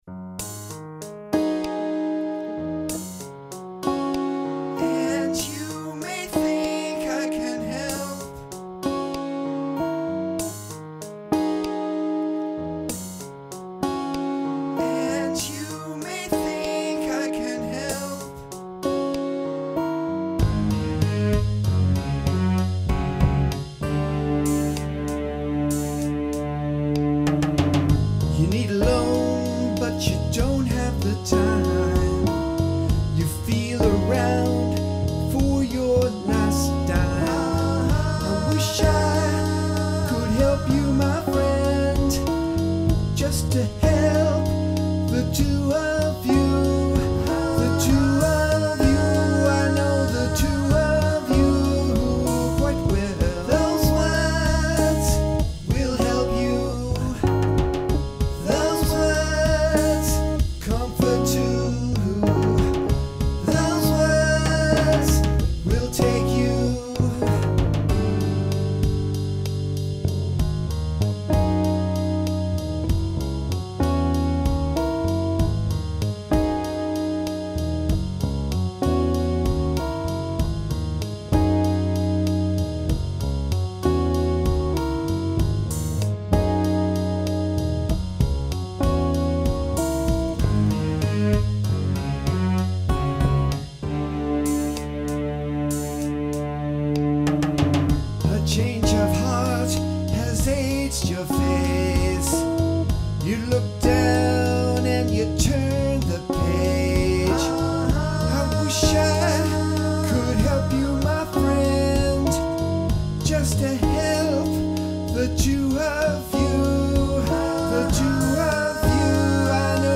percussion
keyboards
Chapman Stick Touchboard guitar
guitar solo